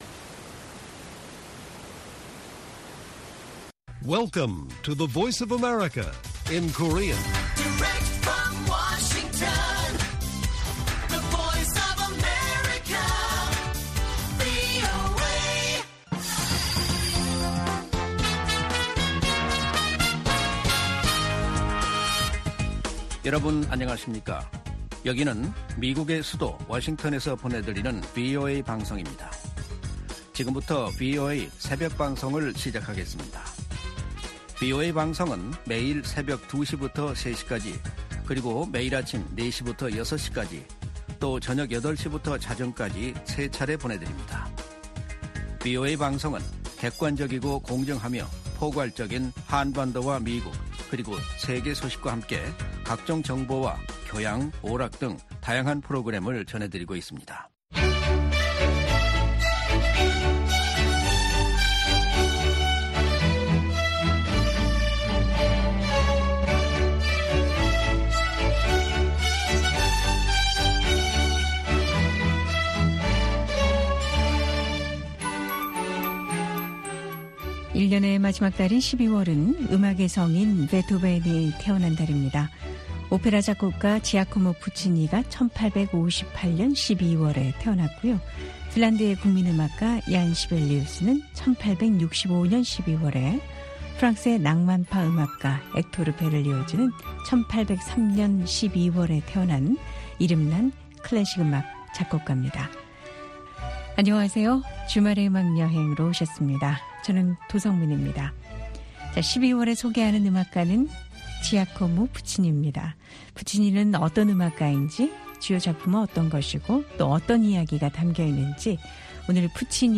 VOA 한국어 방송의 일요일 새벽 방송입니다.